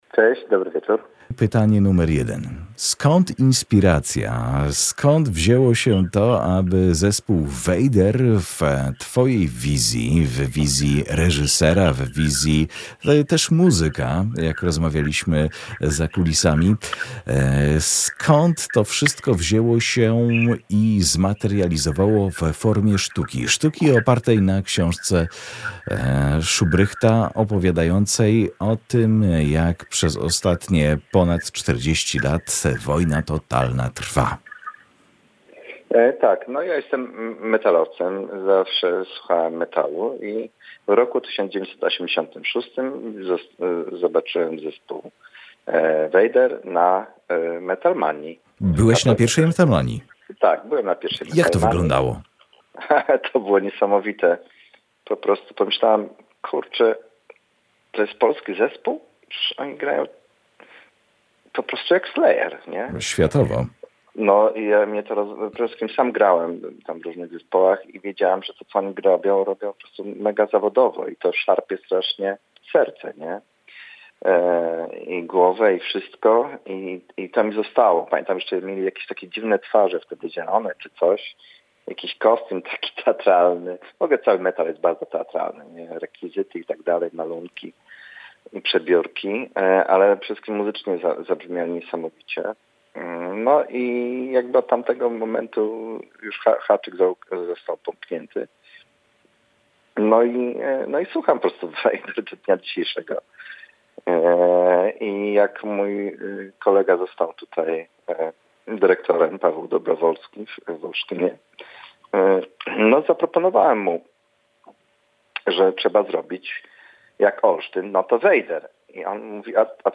Vader w teatrze. Rozmowa